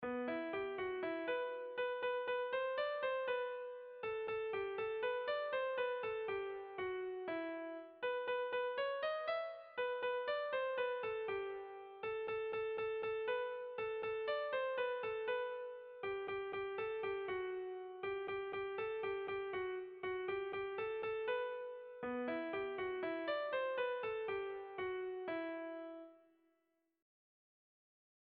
Sentimenduzkoa
Hamalaukoa, txikiaren moldekoa, 9 puntuz (hg) / Bederatzi puntukoa, txikiaren moldekoa (ip)